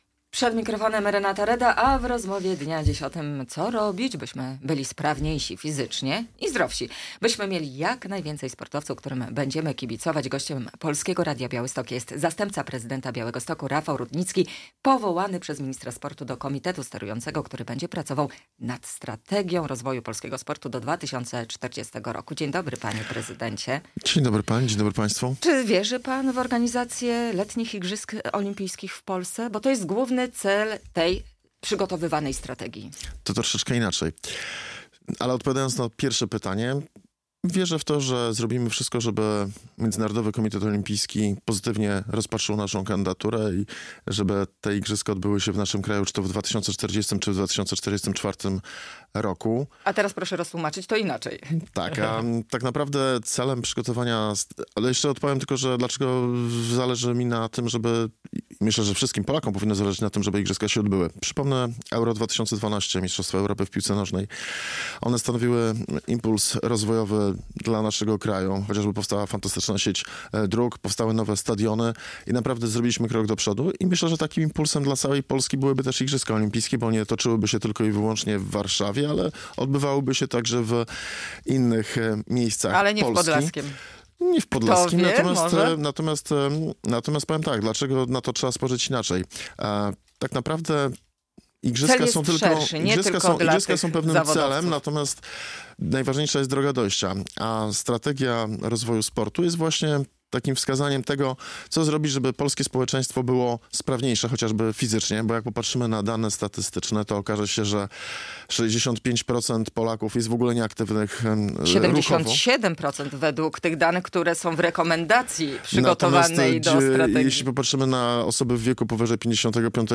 Radio Białystok | Gość | Rafał Rudnicki - zastępca prezydenta Białegostoku